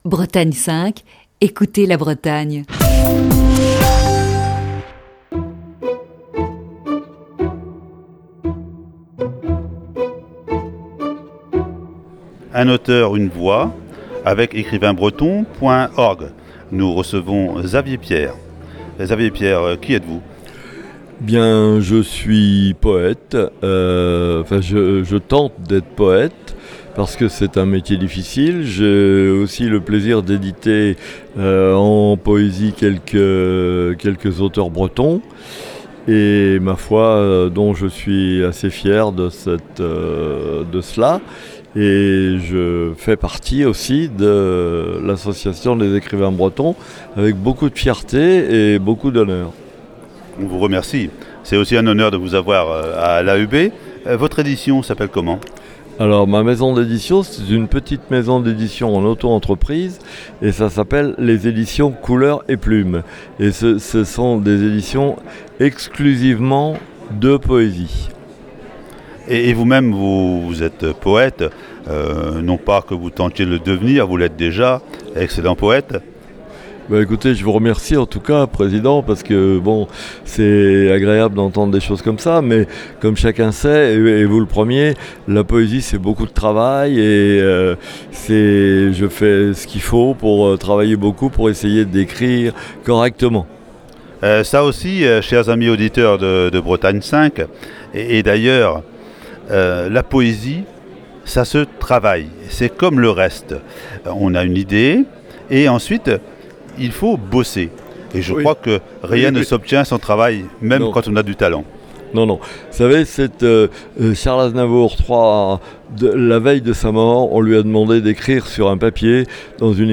(Entretien enregistré au Salon du livre de Plestin-les-Grèves).